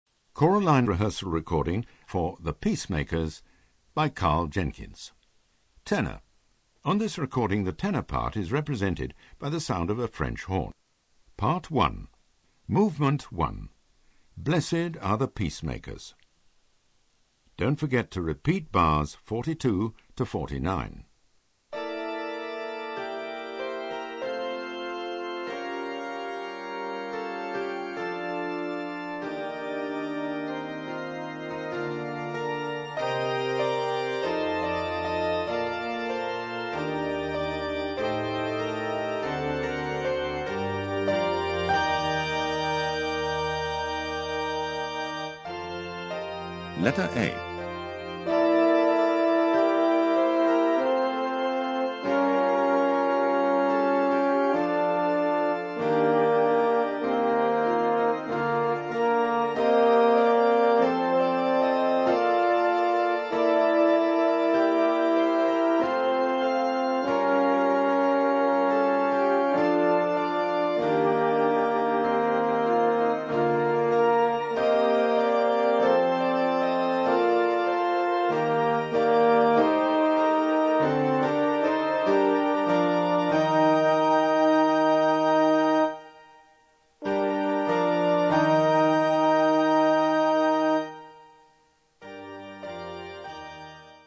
Tenor
Easy To Use narrator calls out when to sing
Don't Get Lost narrator calls out bar numbers
Vocal Entry pitch cue for when you come in